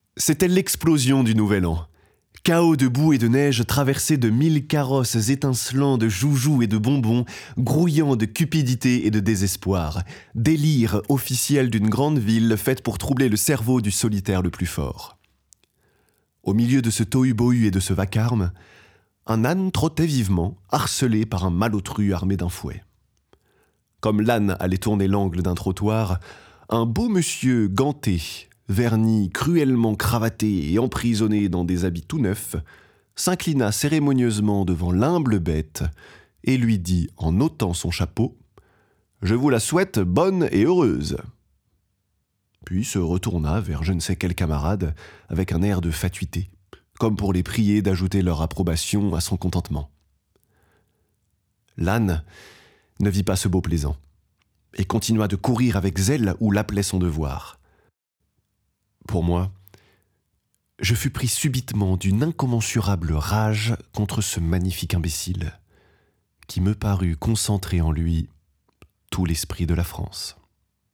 22 - 45 ans - Baryton